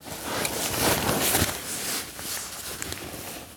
foley_sports_bag_movements_08.wav